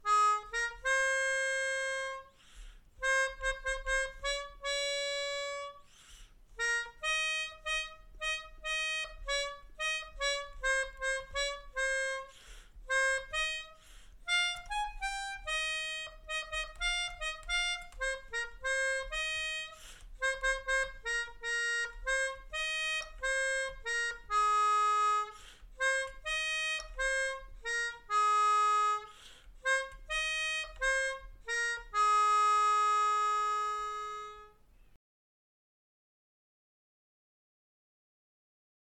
Melodica / Pianica / Airboard